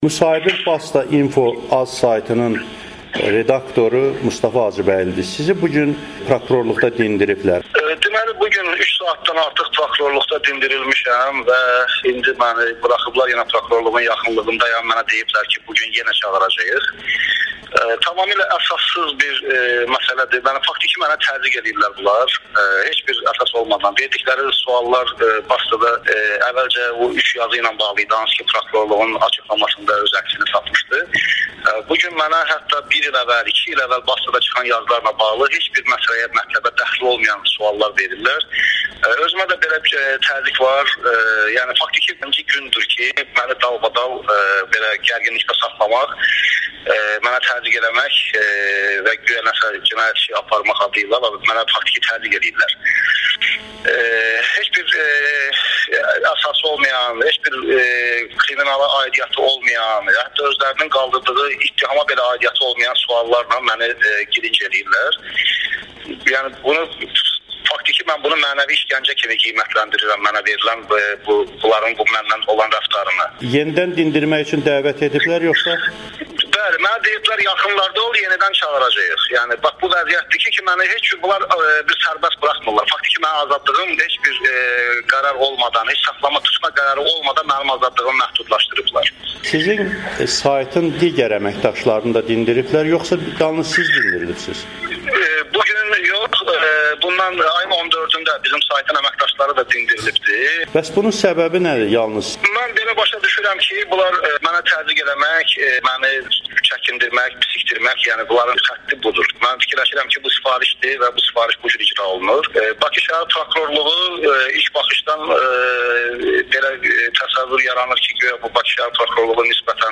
Mənə təzyiq var [Audi-müsahibə]